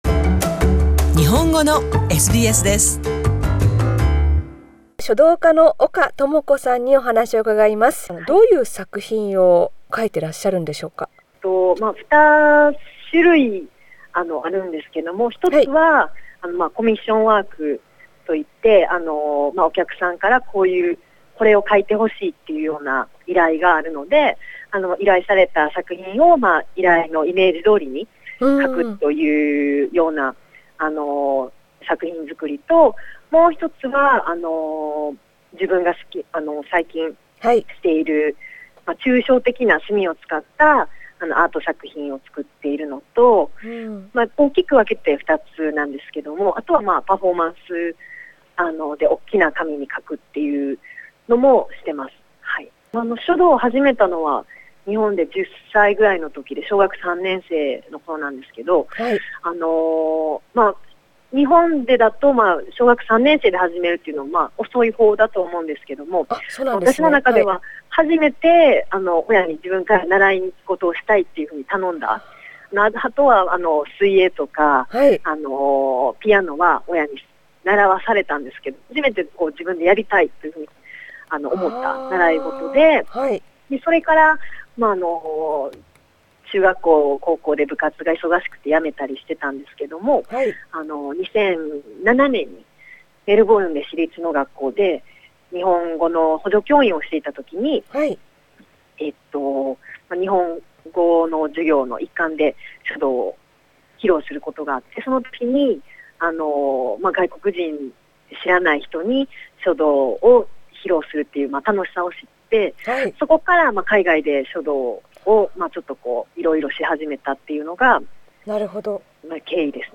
インタビューでは、書道を始めたきっかけや、海外でその楽しさを再発見したいきさつ。